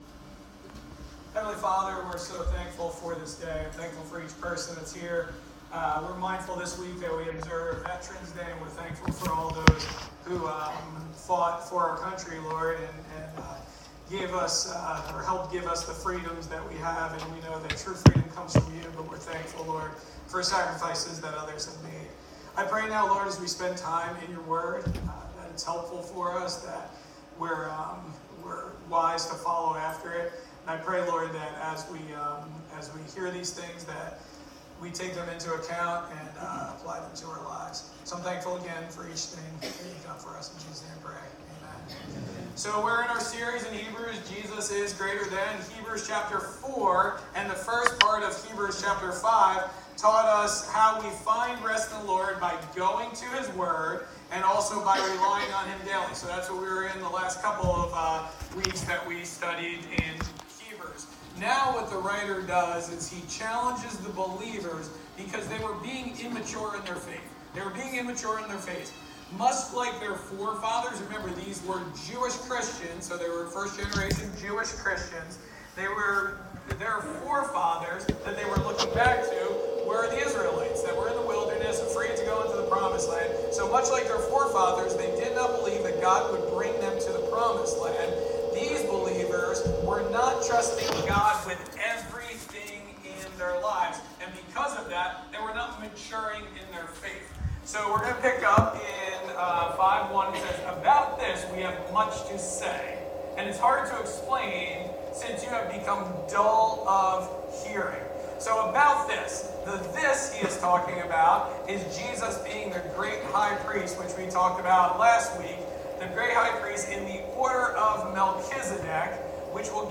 Sorry, we had technical difficulties, so the audio is not that good, and we have no video.